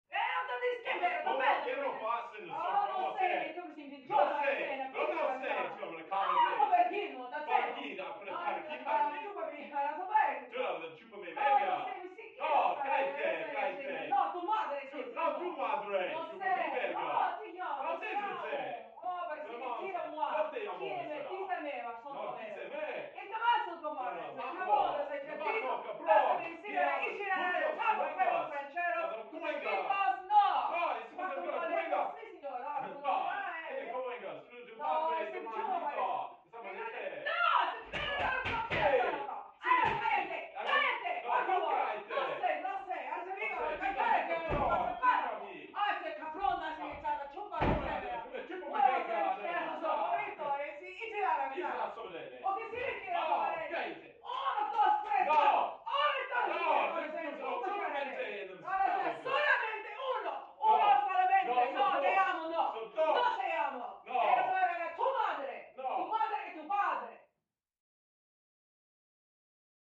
Argue; Could Be Spanish-italian Couple Yelling At Each Other, From Next Room.